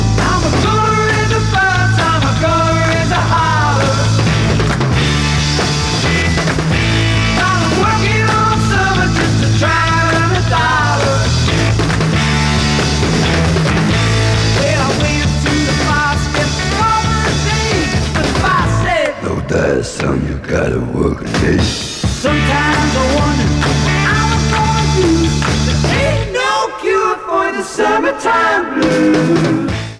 BBC recordings.
Sound Samples/Track Listing (All Tracks In Mono)